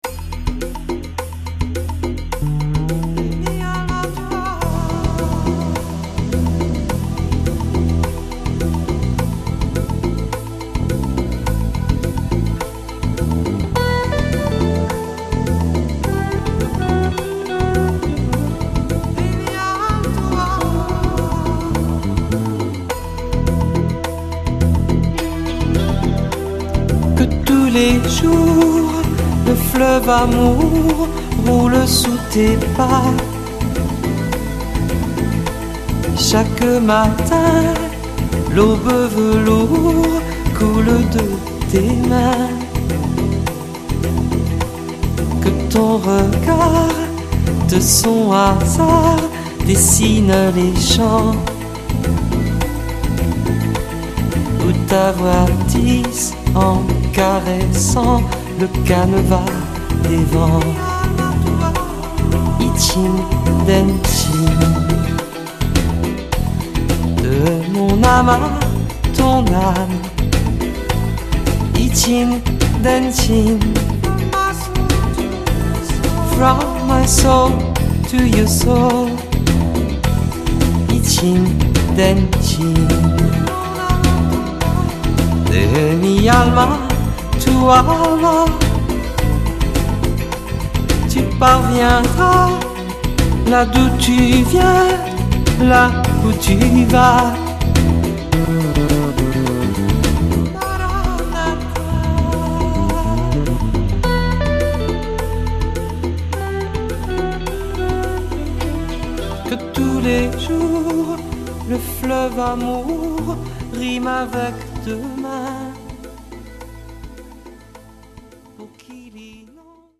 au studio l'hiver 2005